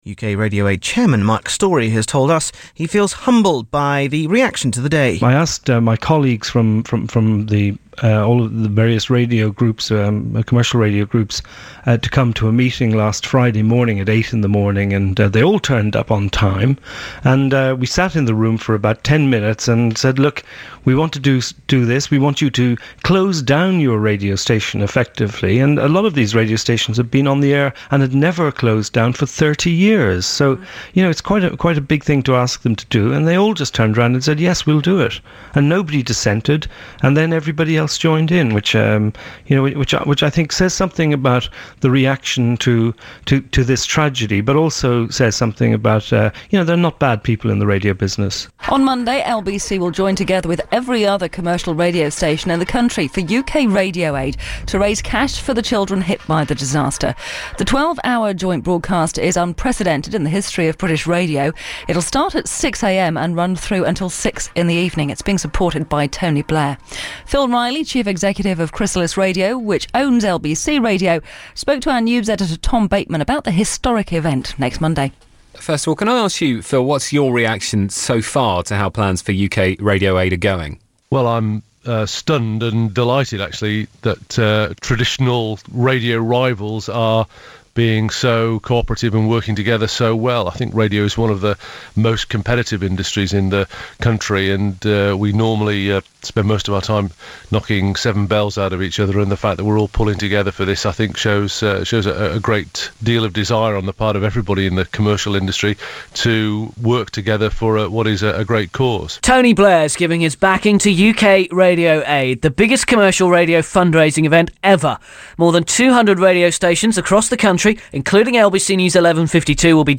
All usual output was dropped in favour of a 12 hour show dedicated to the cause, live from Leicester Square with some regional opt outs.